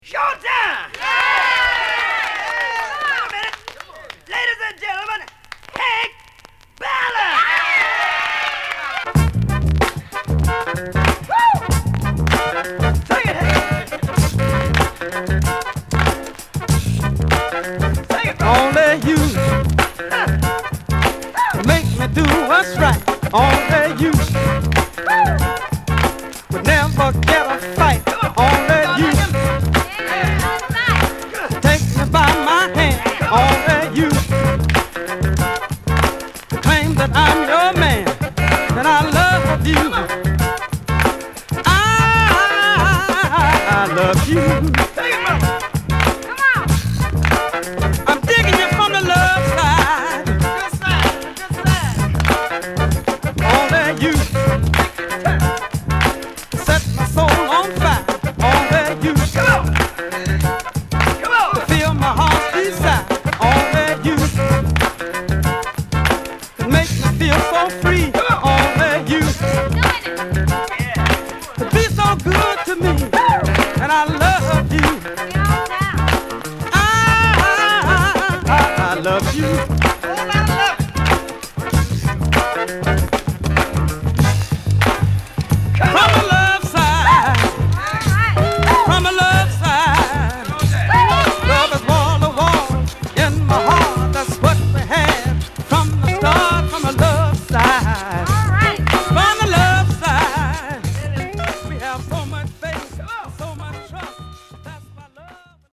グルーヴィーなミディアム・ファンクにしてレア・グルーヴ・クラシック！